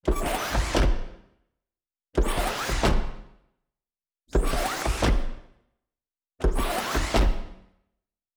SFX_RoboSteps_Squeaky_02.wav